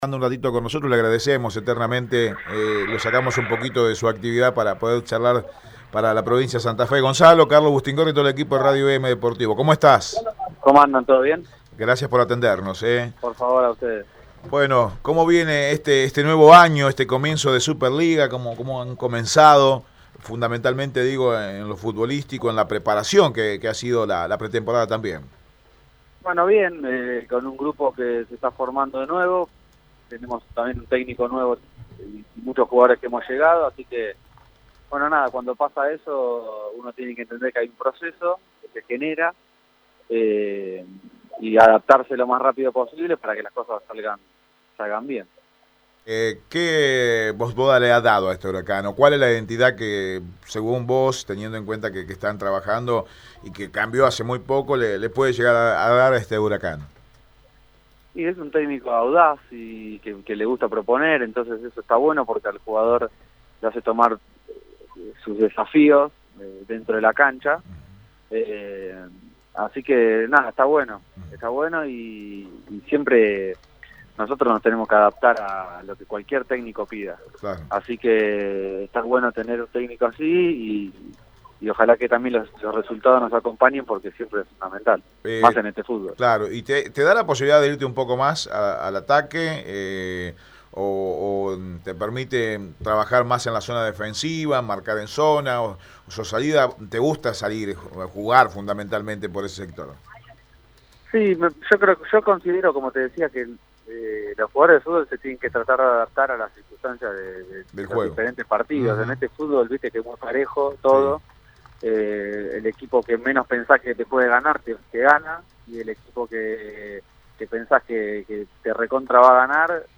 Deportes
JUGADOR-DE-huracan.mp3